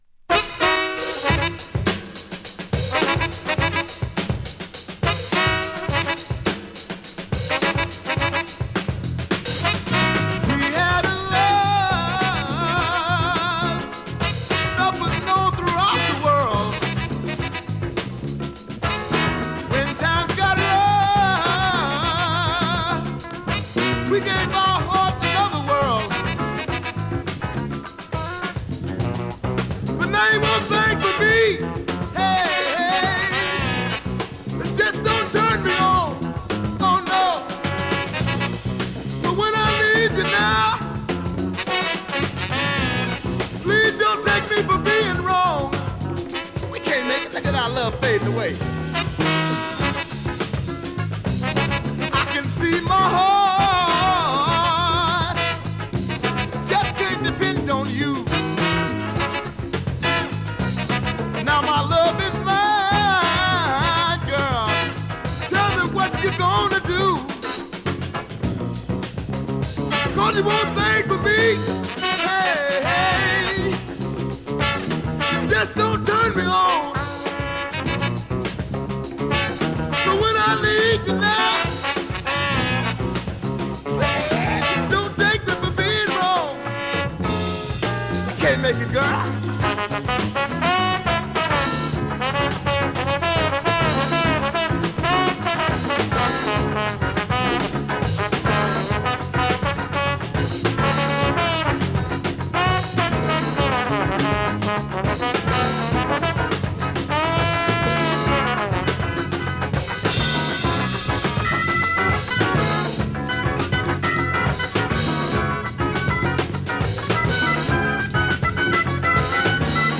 7"Single 45 RPM現物の試聴（両面すべて録音時間）できます。